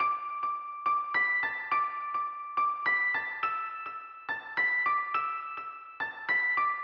钢琴2
Tag: 140 bpm Rap Loops Piano Loops 1.15 MB wav Key : G